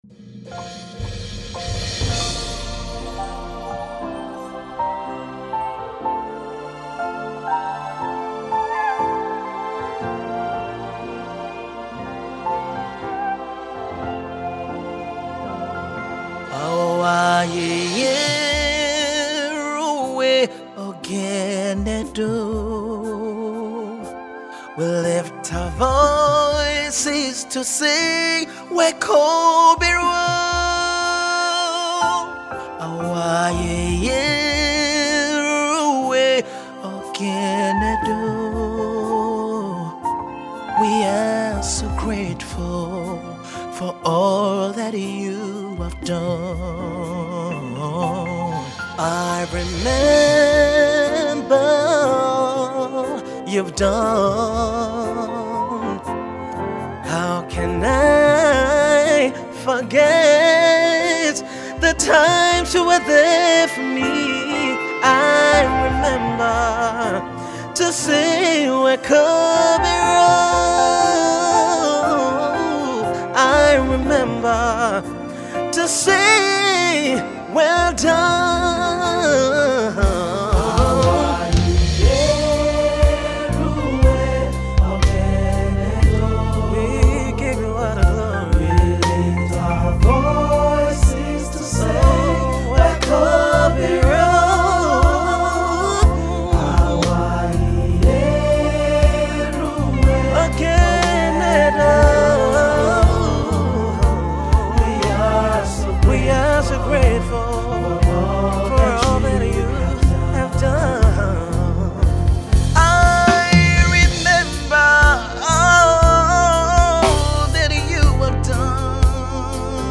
Sensational gospel music duo
a simple, soulful and deep thanksgiving/worship piece